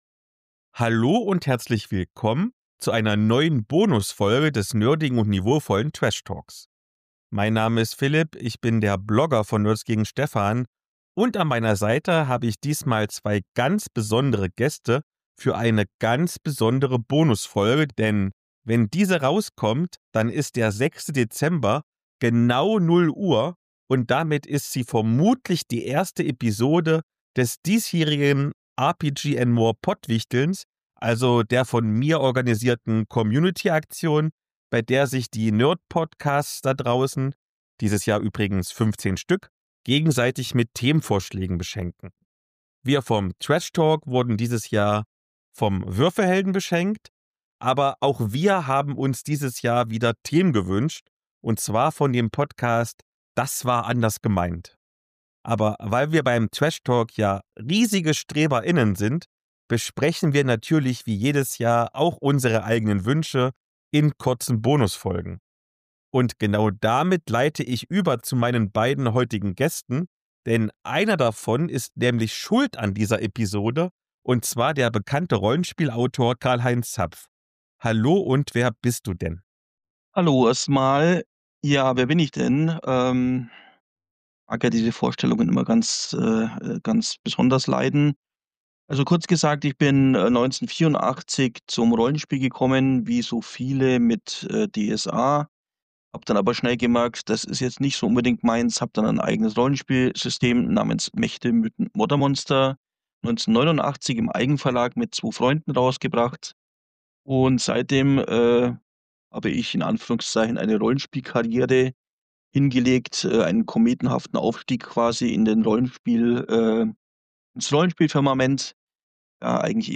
Als Gäste dabei sind der streitbare LARP- & Rollenspiel-Con-Organisator